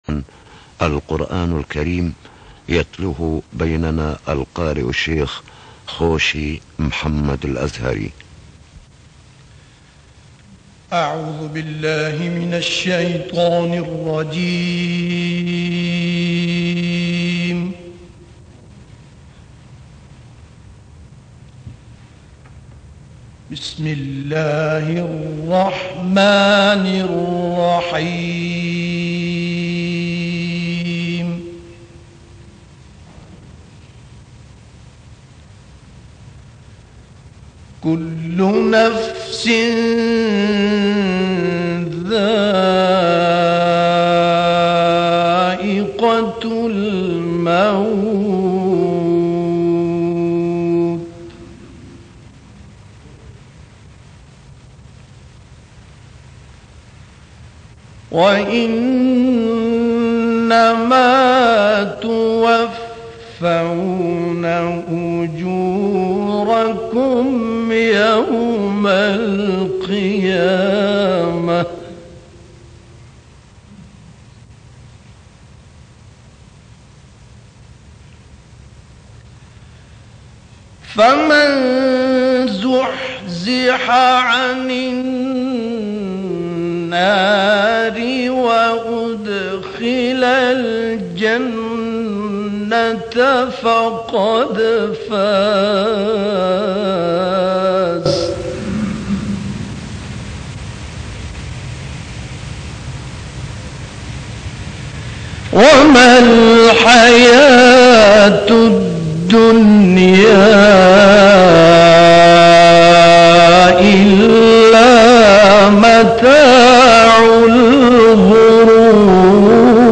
Tilawat